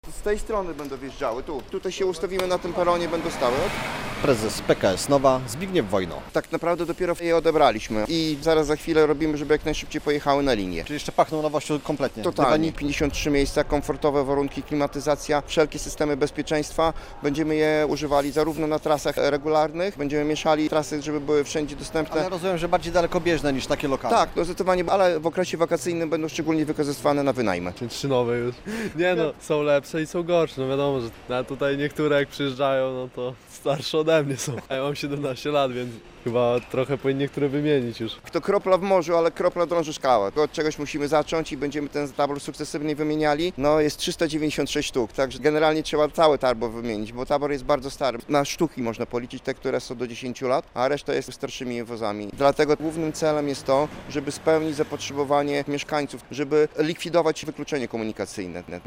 relacja
Prezentacja nowych samochodów odbyła się na białostockim dworcu.